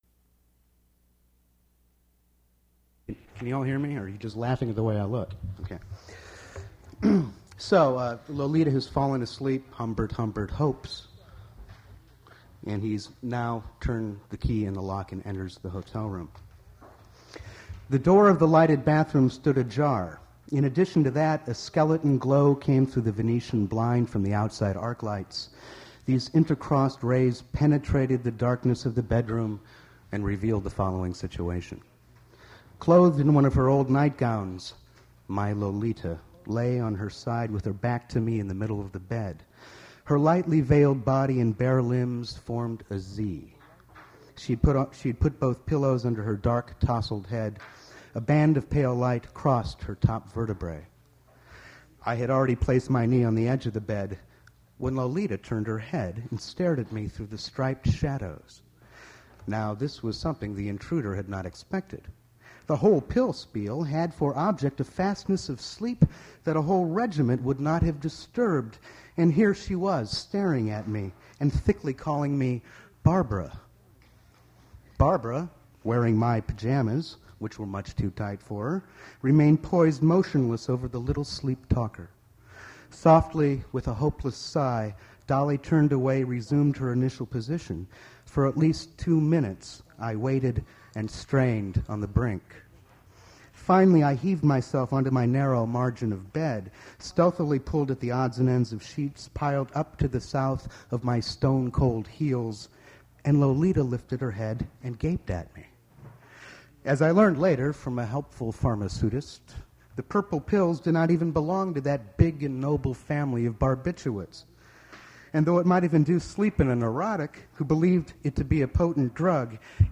Prose reading
mp3 edited access file was created from unedited access file which was sourced from preservation WAV file that was generated from original audio cassette. Language English Identifier CASS.733 Series River Styx at Duff's River Styx Archive (MSS127), 1973-2001 Note Incomple recording; begins mid-reading.